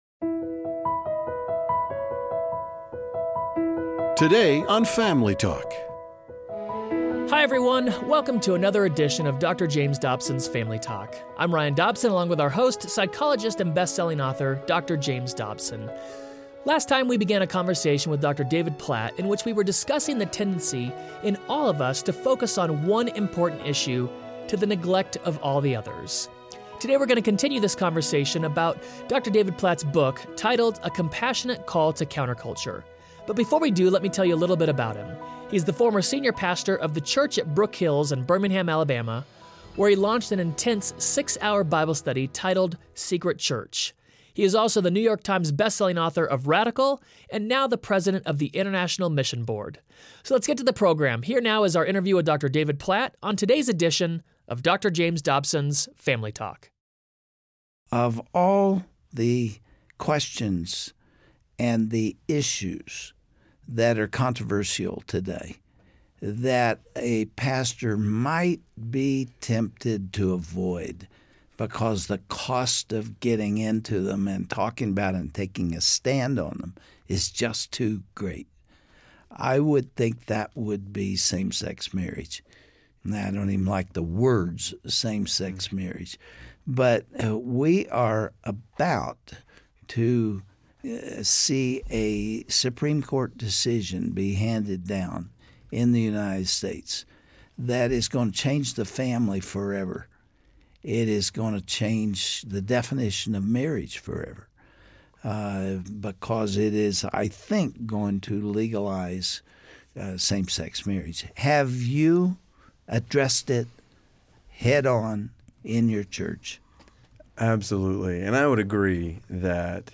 What does it look like to live out the Gospel in today's world? Dr. James Dobson interviews David Platt on impacting the culture for Christ.